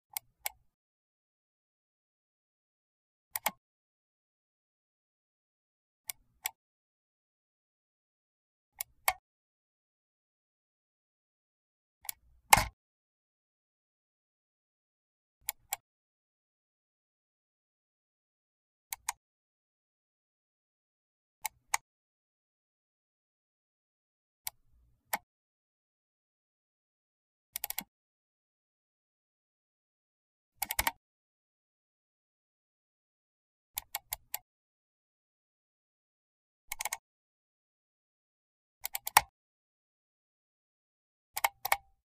Звуки клика мышкой
Клик мышки с разной силой нажатия nРазличные варианты клика мышкой по силе нажатия nКак кликать мышью с разной силой nВарианты силы нажатия при клике мышкой